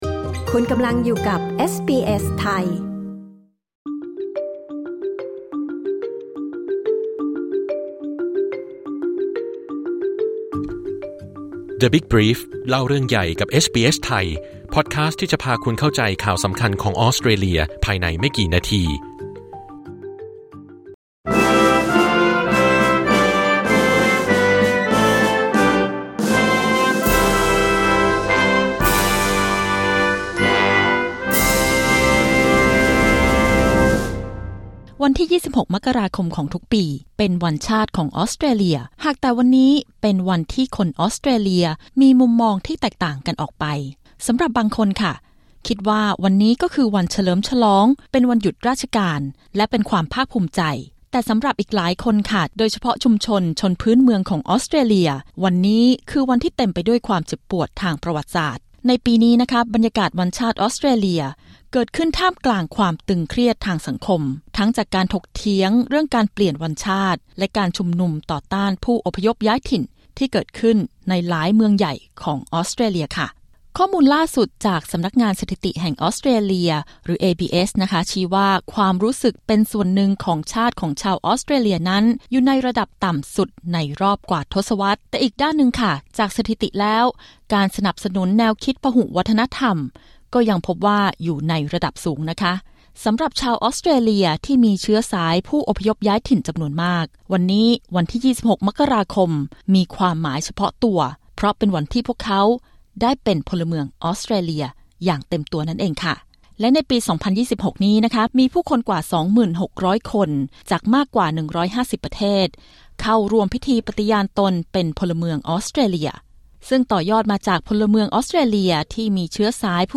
ผู้อพยพย้ายถิ่นทั้งห้าคนจากหลากหลายชุมชน ร่วมแบ่งปันเหตุผลว่าทำไมพวกเขาจึงภาคภูมิใจที่ได้เป็นชาวออสเตรเลีย แม้ในปัจจุบันสังคมจะเผชิญคำถามด้านความสมานสามัคคี